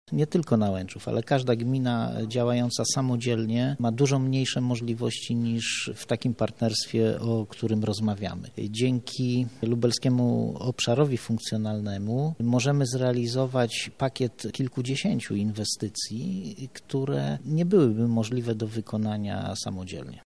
konferencja miast 2
– mówi Artur Rumiński, zastępca burmistrza Nałęczowa.